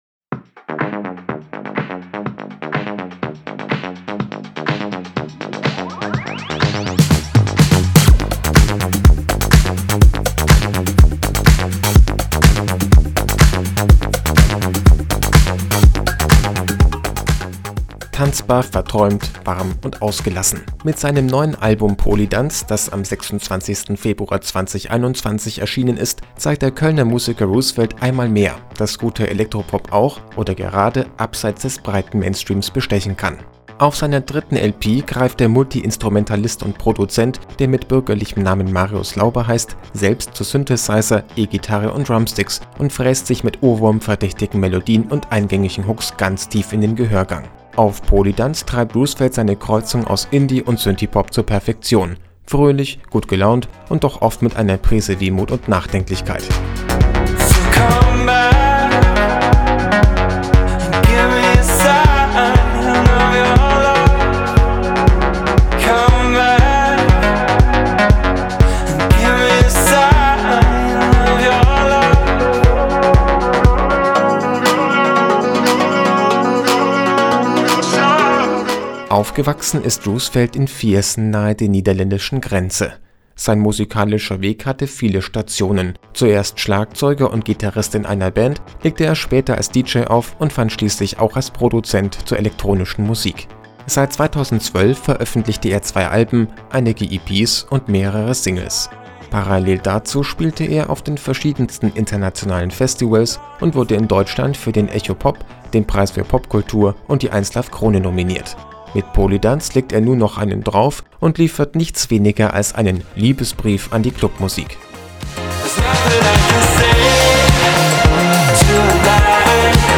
Tanzbar, verträumt, warm und ausgelassen – mit seinem neuen Album “Polydans”, das am 26. Februar 2021 erschienen ist, zeigt der Kölner Musiker Roosevelt einmal mehr, dass guter Elektropop auch – oder gerade – abseits des breiten Mainstreams bestechen kann. Auf seiner dritten LP greift der Multiinstrumentalist und Produzent, der mit bürgerlichem Namen Marius Lauber heißt, selbst zu Synthesizer, E-Gitarre und Drumsticks – und fräst sich mit ohrwurmverdächtigen Melodien und eingängigen Hooks ganz tief in den Gehörgang. Auf “Polydans” treibt Roosevelt seine Kreuzung aus Indie- und Synthiepop zur Perfektion: fröhlich, gut gelaunt und doch oft mit einer Prise Wehmut und Nachdenklichkeit.
Trotz all dieser Referenzen steht “Polydans” auf eigenen Beinen: Neben federnden Beats, funky Bassriffs und springenden Rhythmen laden die melancholisch angehauchten Vocals auch ein bisschen zum Träumen ein. Besonderer Pluspunkt ist das Zusammenspiel von natürlichen und elektronischen Instrumenten, das dem organischen Sound etwas Bodenständiges verleiht.